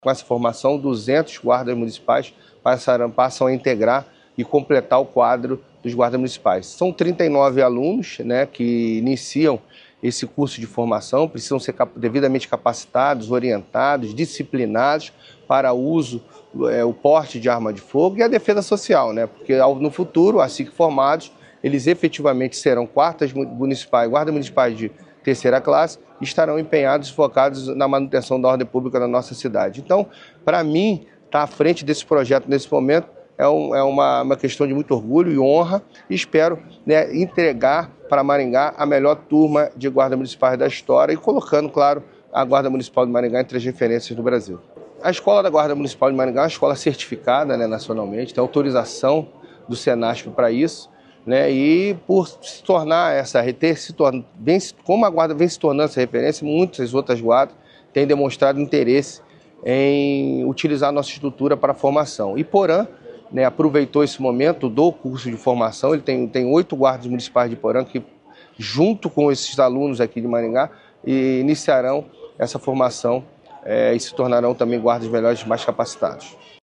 O secretário de Segurança Luiz Alves diz que com a formação de novos 39 agentes, a Guarda chega a 200 integrantes.